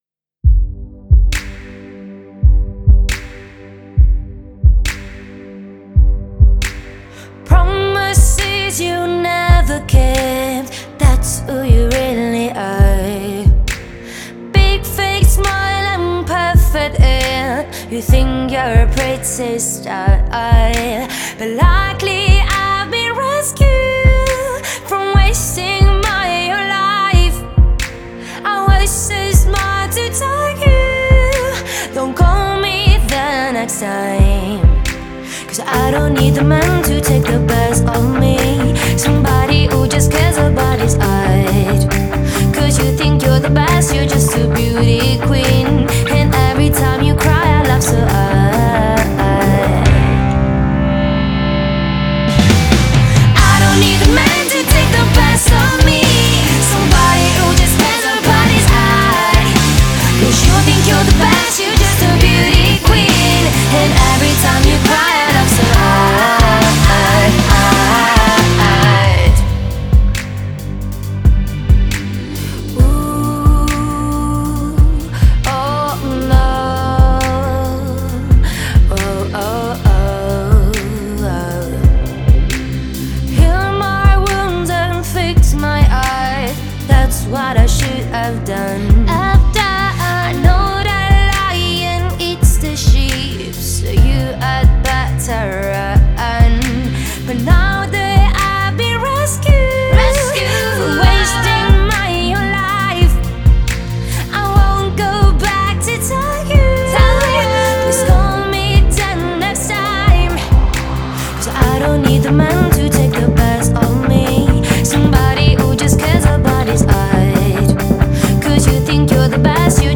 это энергичная поп-песня